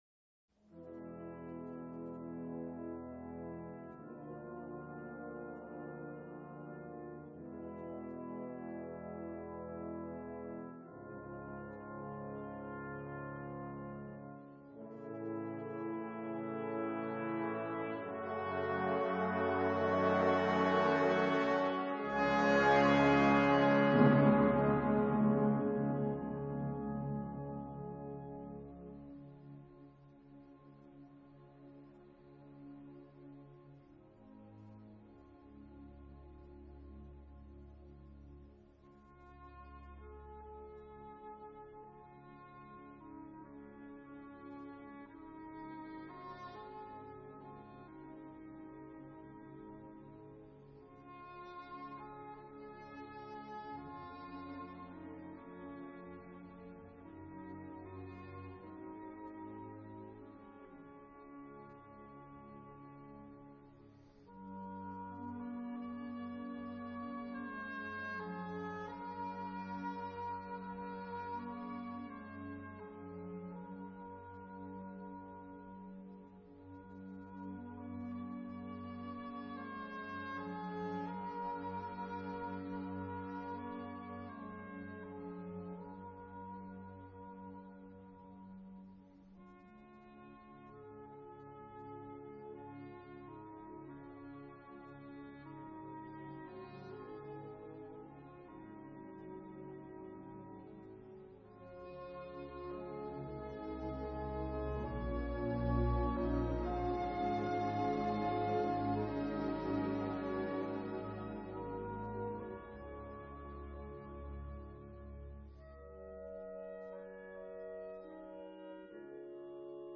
（也有的版本译为“新大陆”或“新世界”交响曲） 全曲共分为四个乐章： 第二乐章 最缓板，降D大调，4/4拍子，复合三段体。
整个乐队的木管部分在低音区合奏出充满哀伤气氛的几个和弦之后，由英国管独奏出充满奇异美感和神妙情趣的慢板主题，弦乐以简单的和弦作为伴奏，这就是本乐章的第一主题,此部分被誉为所有交响曲中最为动人的慢板乐章。
本乐章的第二主题由长笛和双簧管交替奏出， 旋律优美绝伦， 在忽高忽低的情绪中流露出了一种无言的凄凉，仍是作者思乡之情的反映。 本乐章的第三主题转为明快而活泼的旋律，具有一些捷克民间舞蹈音乐的风格。